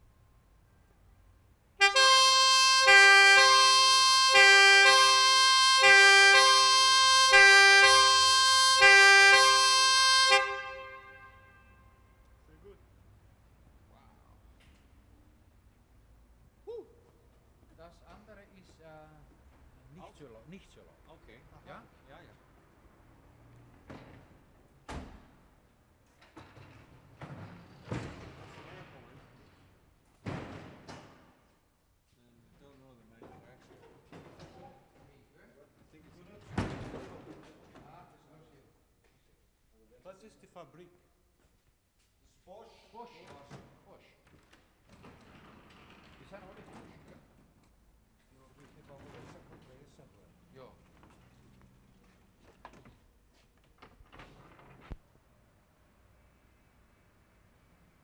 5 - 11. SIRENS (5-6 police siren, 7-11 fire sirens).
6. Whistle recorded outside.
7 - 11. All recorded inside garage.